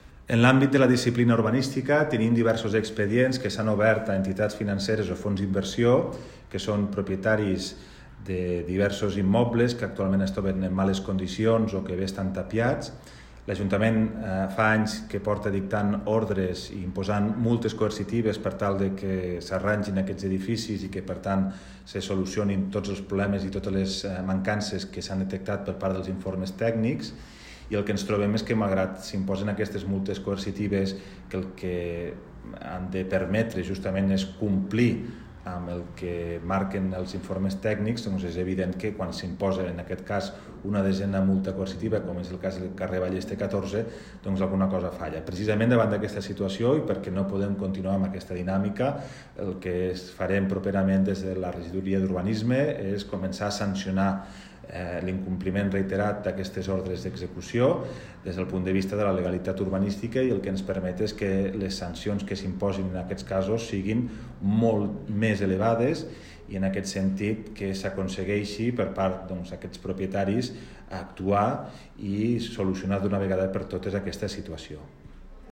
tall-de-veu-del-primer-tinent-dalcalde-toni-postius-sobre-els-canvis-en-les-sancions-urbanistiques